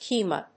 /kiːmə(米国英語)/